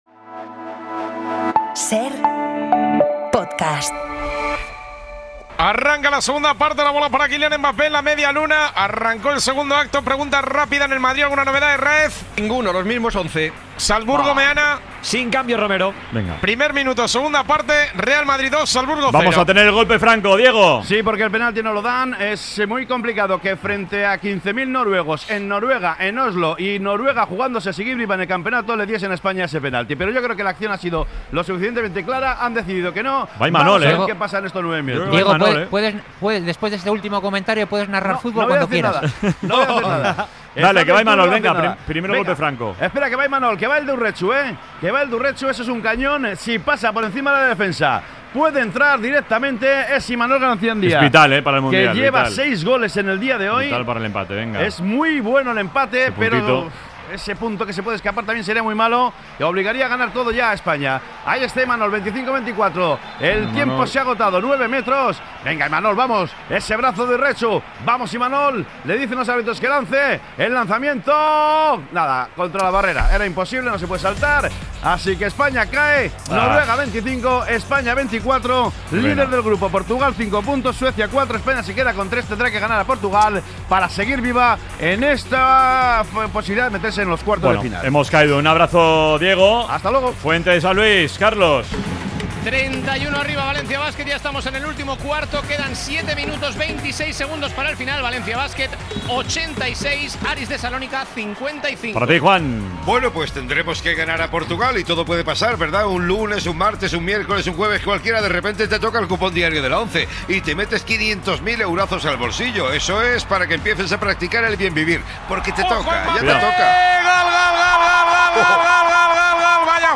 Real Madrid - Salzburgo | 2ª Parte 52:18 SER Podcast Así te narramos la segunda parte del Real Madrid - Salzburgo correspondiente a la jornada 7 de la Champions League 24/25.